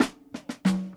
Funky Fill.wav